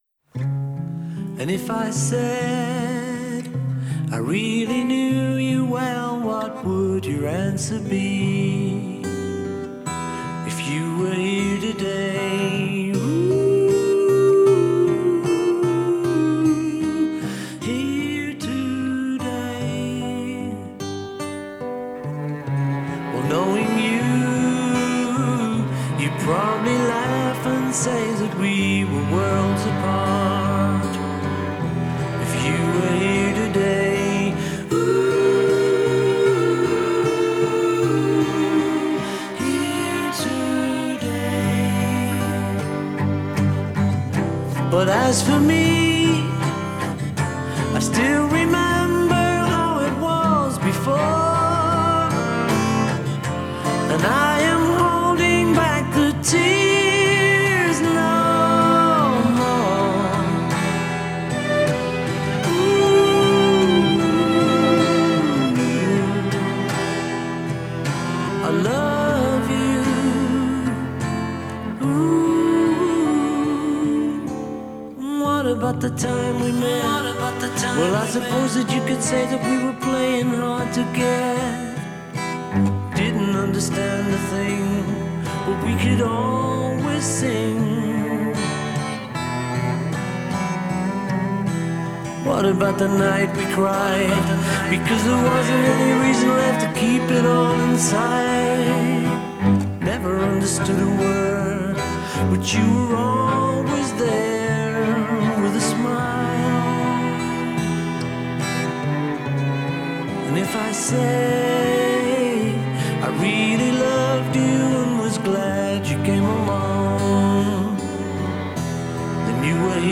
Genero: Rock/Pop
Calidad: Stereo (Exelente)(Remasterizado)